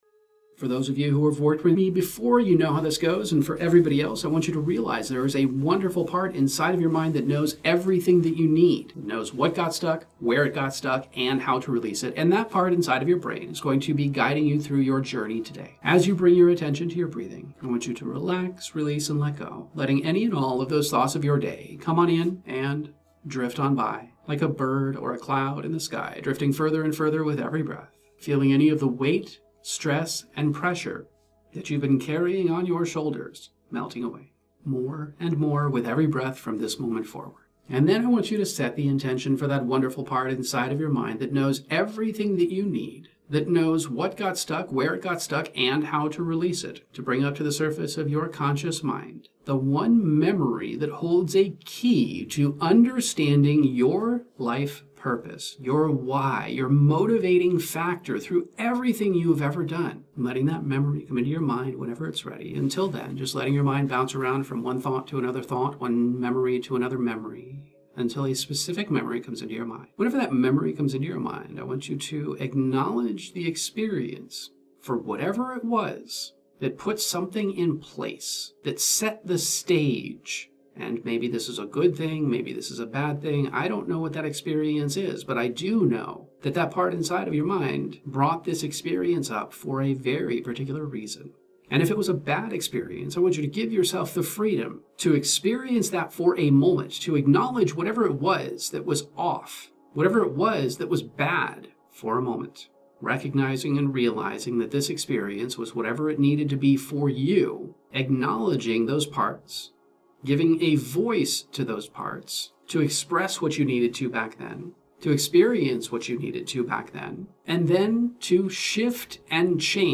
Emotional Optimization™ Meditations